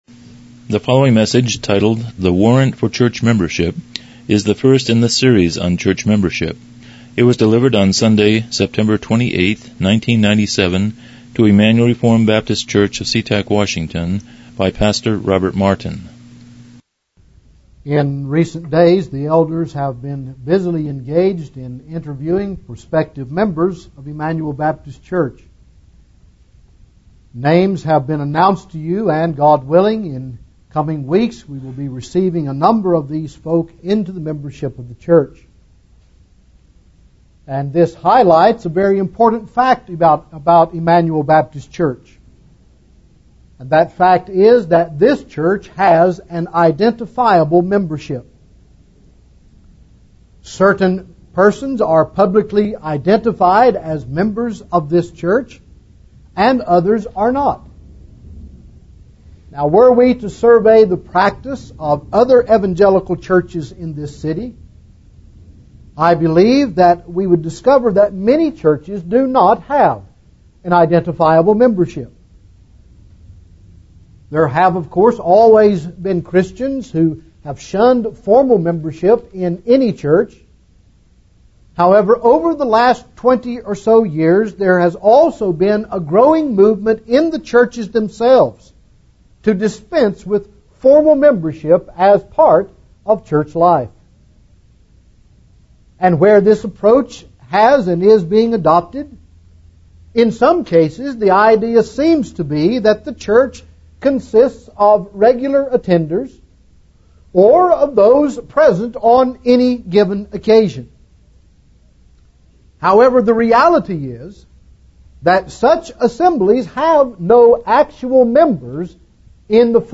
Church Membership Service Type: Morning Worship « Parable of Finding Joy